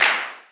Clap MadFlavor 1.wav